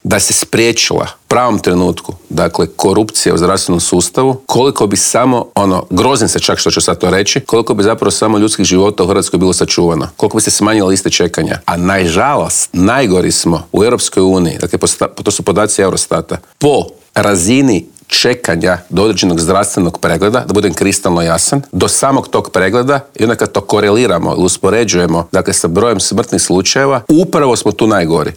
"Na idućim parlamentarim izborima SDP će biti prvi, kandidirao sam se za predsjednika stranke da budem premijer", otkrio nam je u Intervjuu tjedna Media servisa predsjednik najjače oporbene stranke Siniša Hajdaš Dončić.